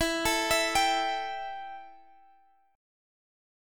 Listen to Embb5 strummed